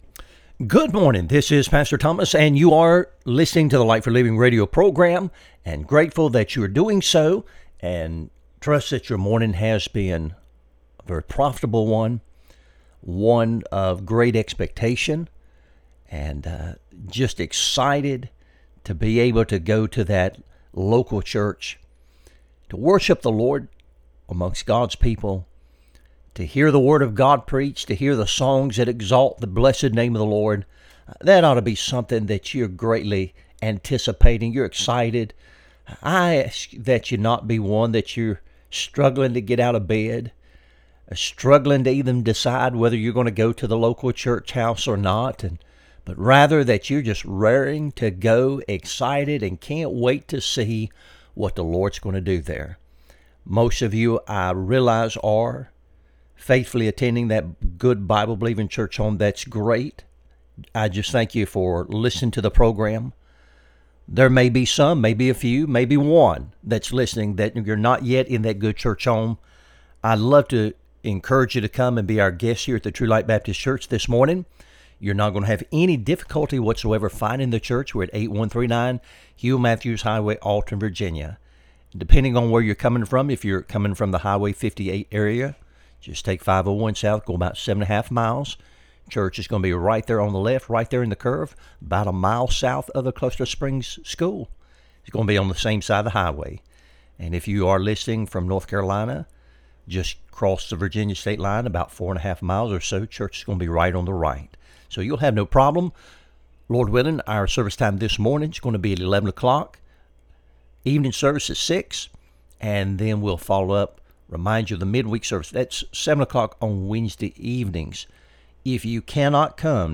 Light for Living Radio Broadcast